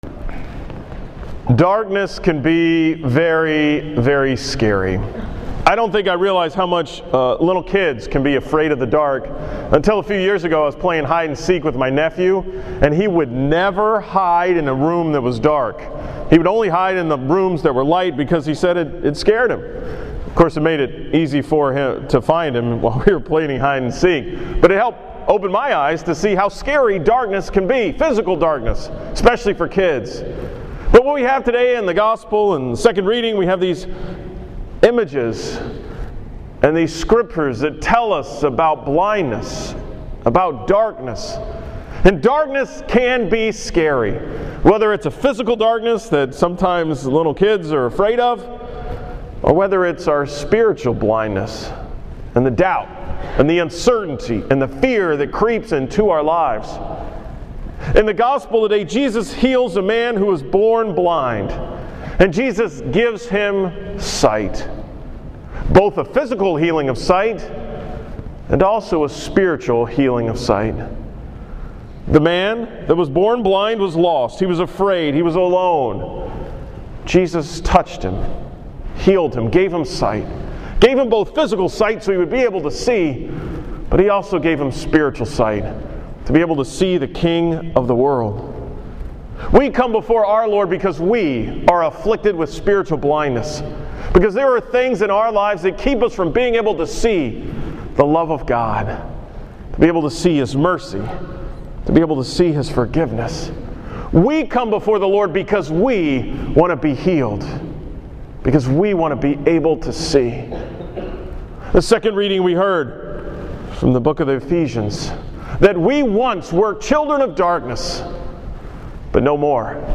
From the 7:30 am Mass on Sunday, March 30th